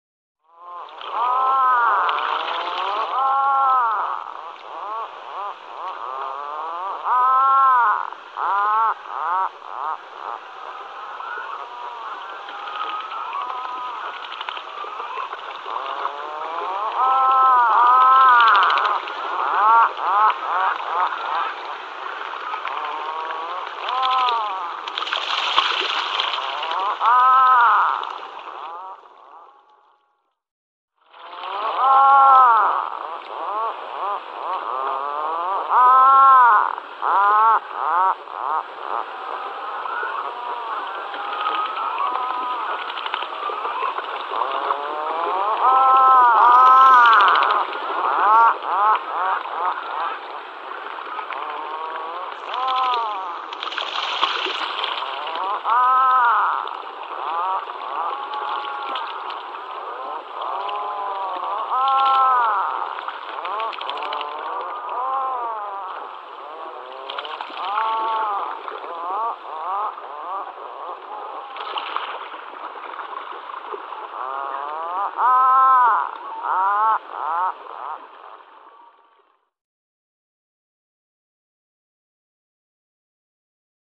Lunnefågel / Atlantic Puffin Fratercula arctica Läte / Sound Du är här > Fåglar / Birds > Lunnefågel / Atlantic Puffin Galleri med utvalda fågelbilder / Favourites Hornøya, mars 2025.
Lunnefagel.mp3